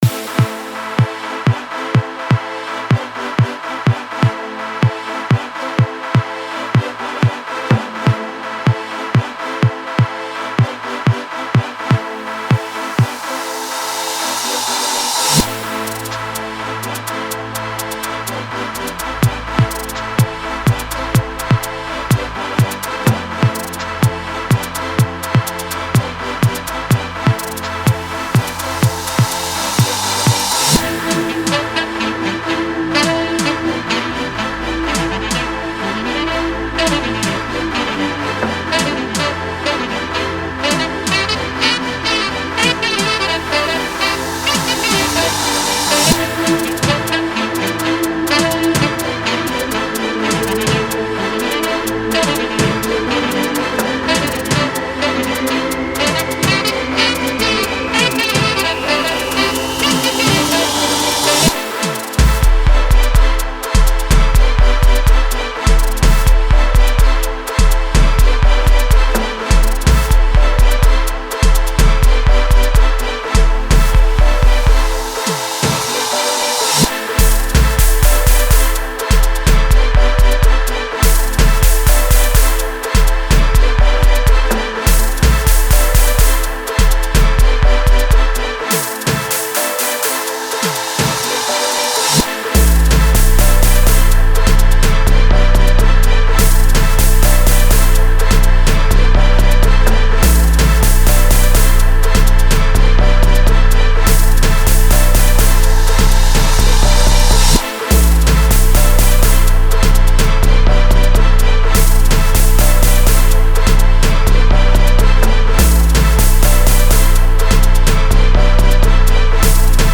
South African singer and songwriter